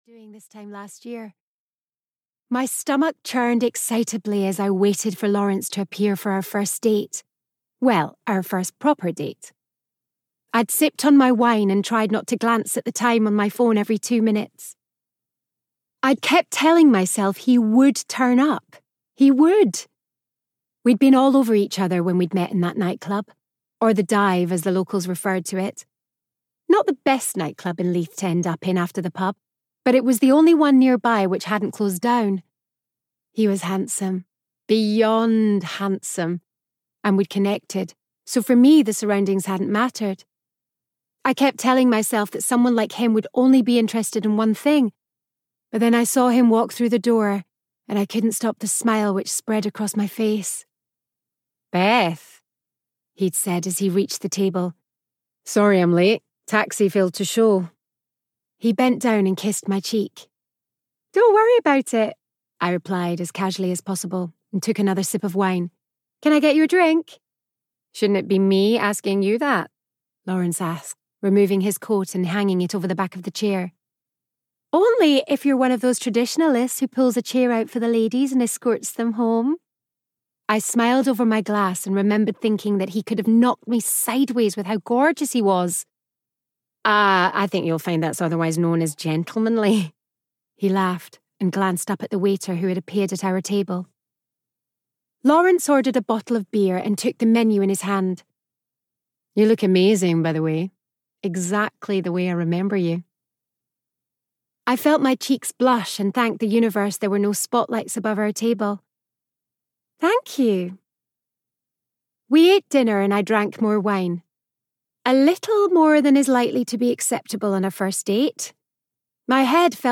Audio knihaWhat She Did (EN)
Ukázka z knihy